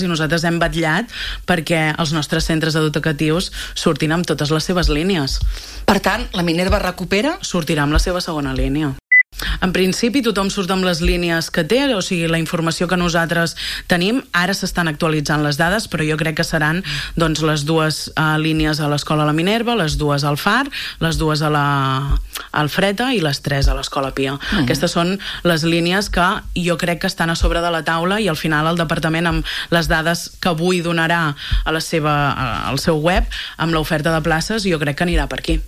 Ho ha avançat la regidora d’Educació, Mariceli Santarén, en declaracions a Ràdio Calella TV, on ha parlat del seguiment que s’ha fet des de l’Ajuntament de Calella: